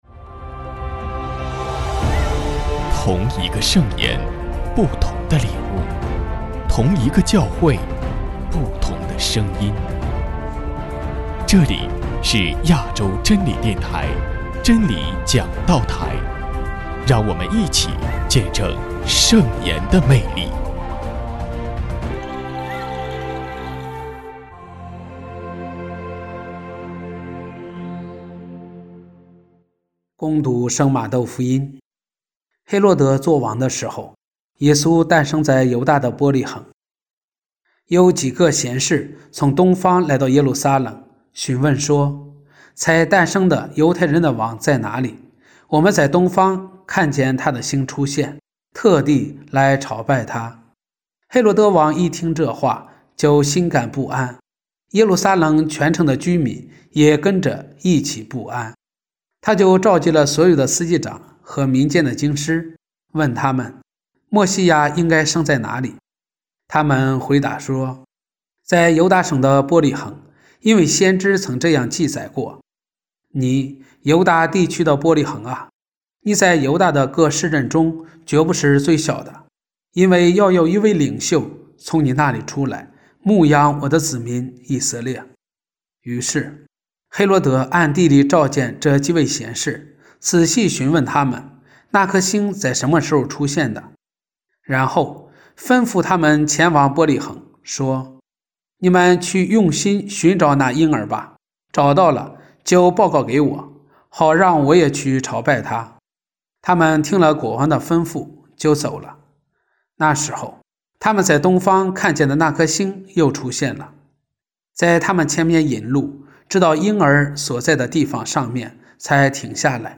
证道：